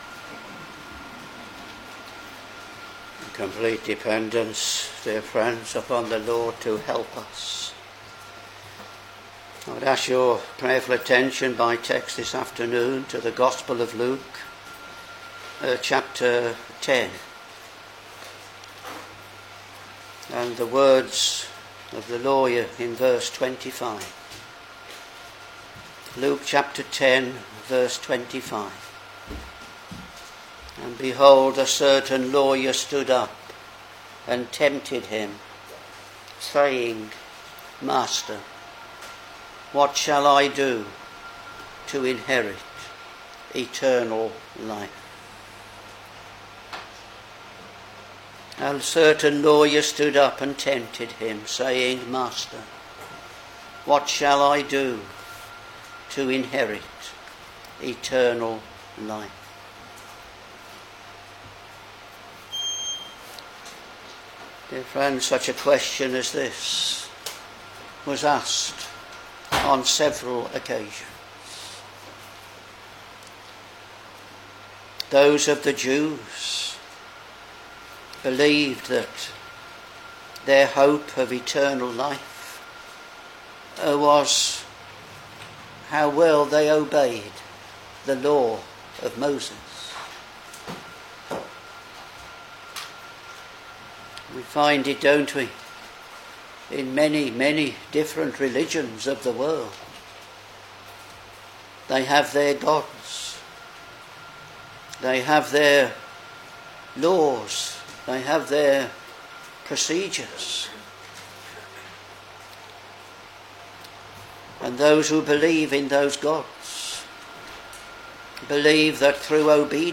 Back to Sermons